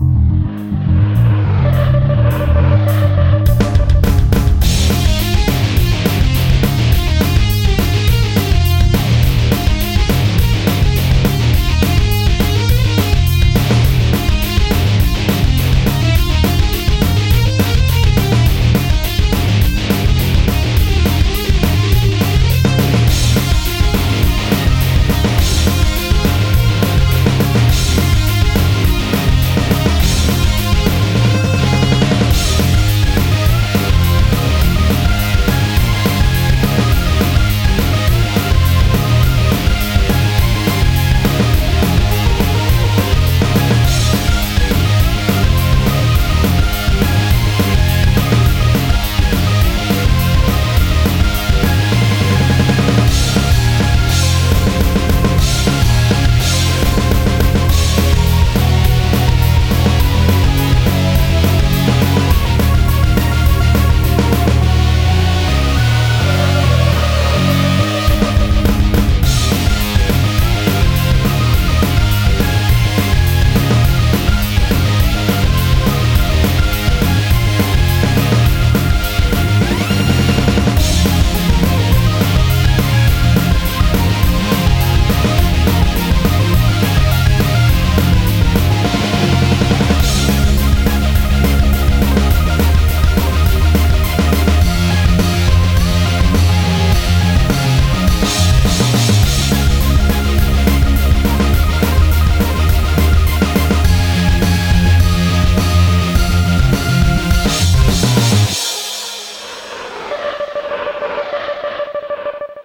BPM104-208
Qualité du MP3Perfect (High Quality)